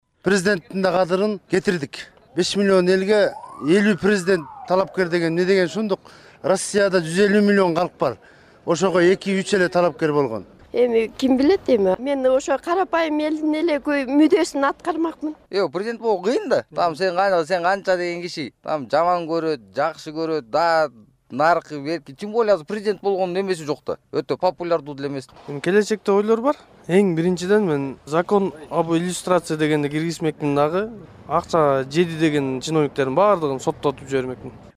Сурамжылоо - Талас